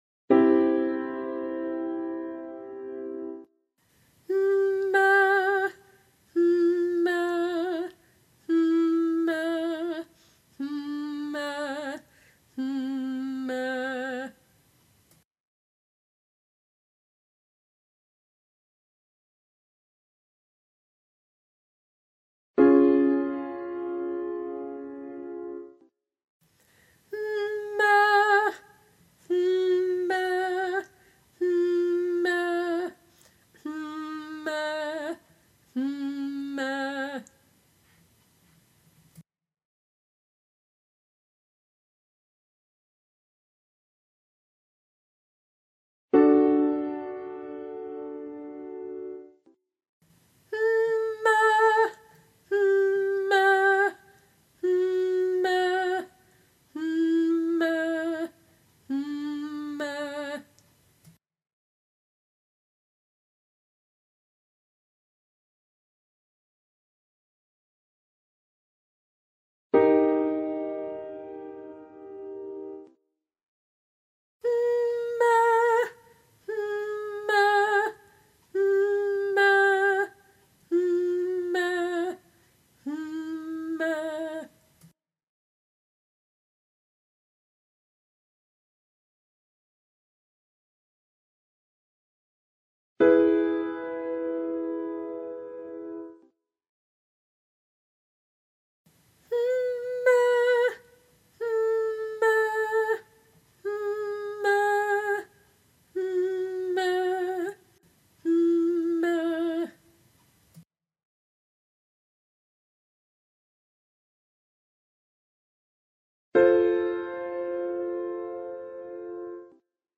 Staccato Triads – Basic
73A Separated /hmm…meh/ as in “dress”